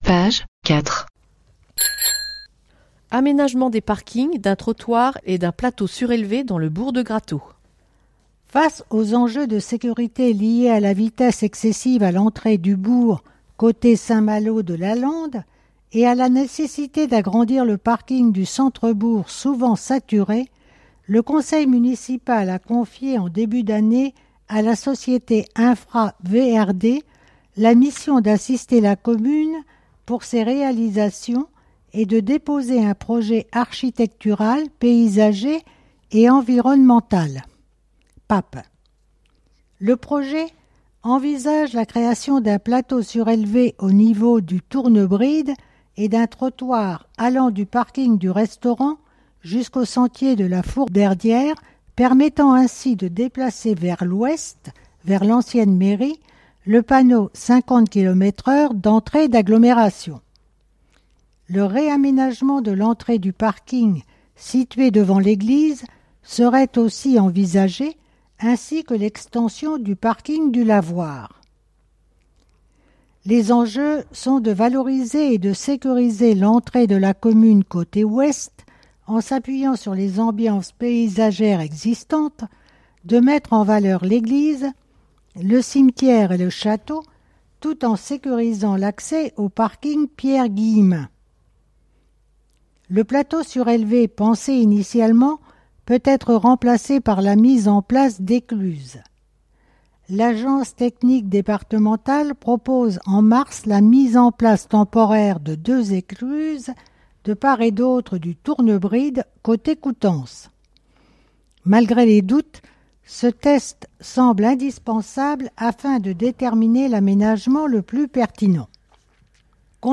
Détails et informations bi-annuelle de la commune, commentées par le maire